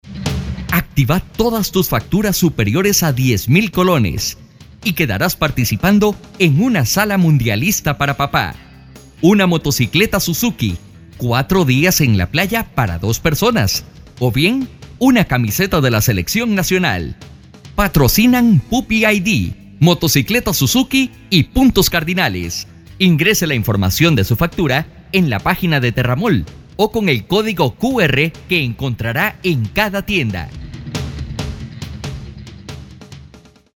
Tengo la versatilidad de manejar español neutro, para video tutoriales, narraciones, comerciales, documentales, etc.
kastilisch
Sprechprobe: Werbung (Muttersprache):